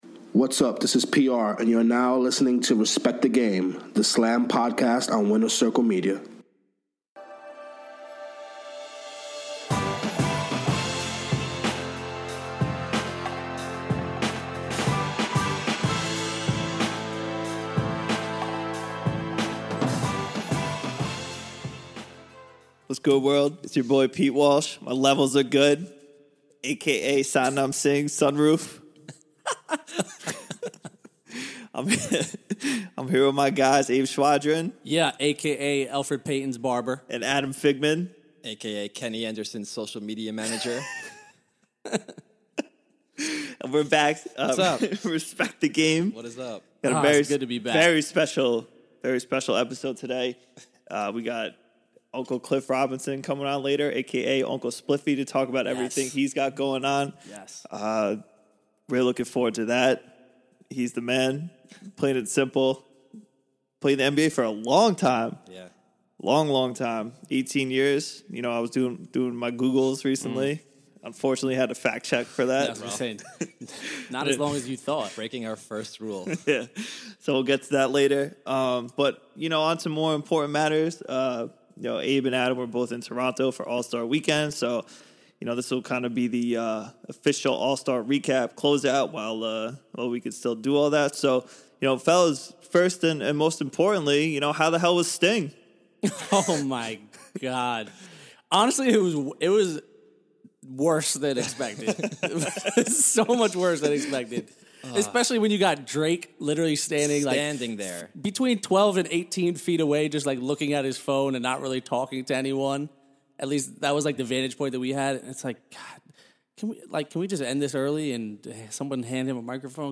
Later they are joined by 18-year NBA veteran Cliff Robinson to talk about his venture into the cannabis world as both an advocate and businessman with Uncle Spliffy, playing against Michael Jordan in the '92 Finals, Kenny Anderson's Twitter use and his trip to North Korea in 2014.